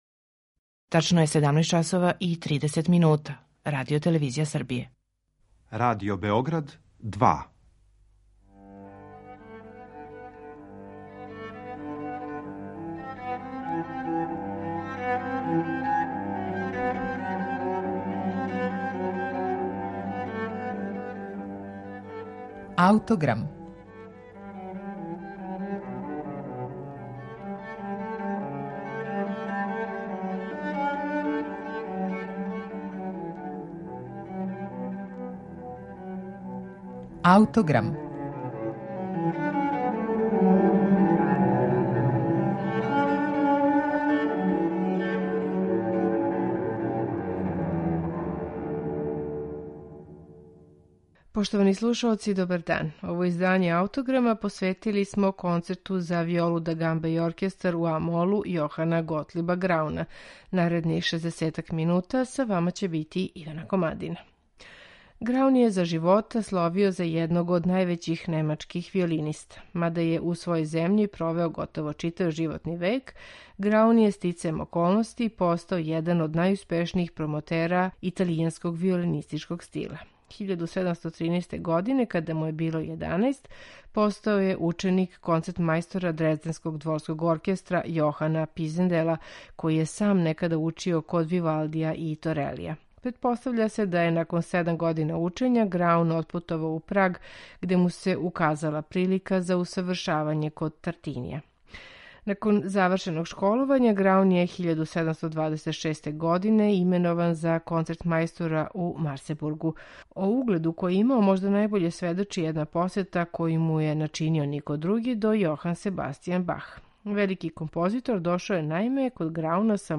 Јохан Граун: Концерти за гамбу
У данашњем Аутограму представићемо га, међутим, као аутора концерта за виолу да гамба, инструмент који је за тај жанр одабрала тек неколицина композитора.